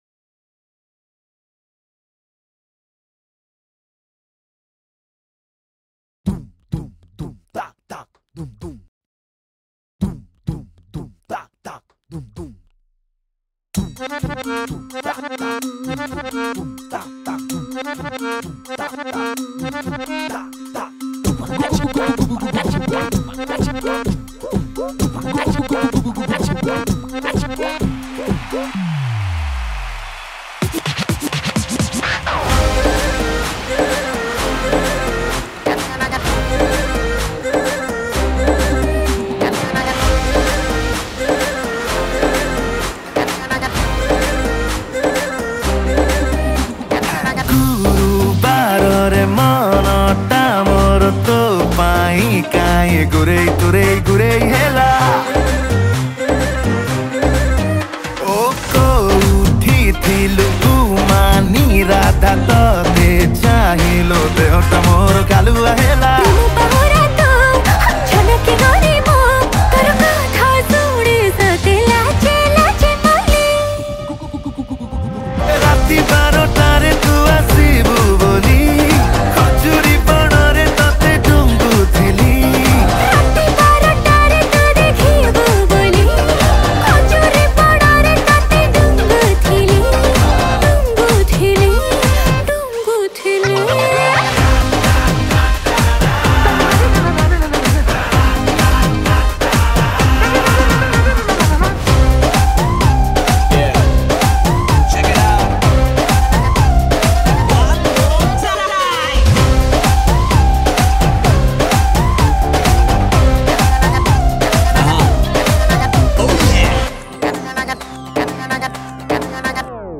Keyboard & Rhythm Programming